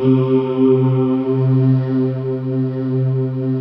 Index of /90_sSampleCDs/USB Soundscan vol.28 - Choir Acoustic & Synth [AKAI] 1CD/Partition D/25-TRUEVOICE
TRUEVOICC3-R.wav